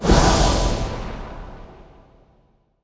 metinstone_attack.wav